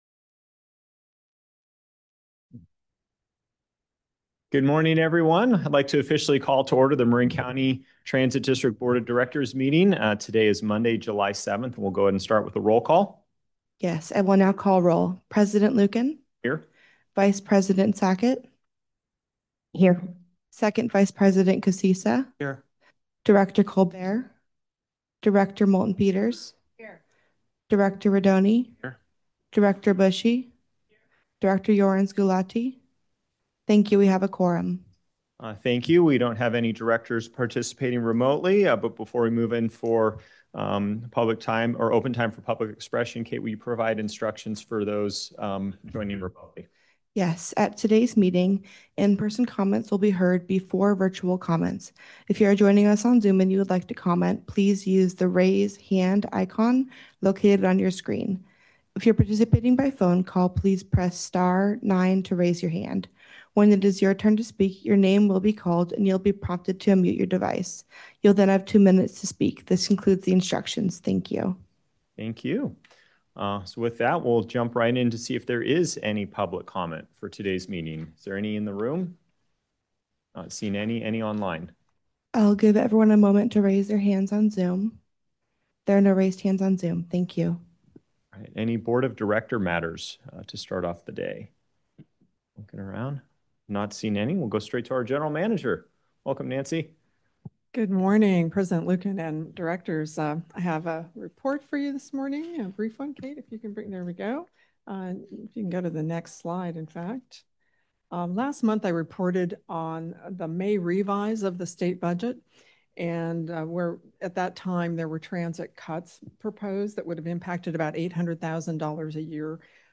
In-person comments will be heard before virtual comments.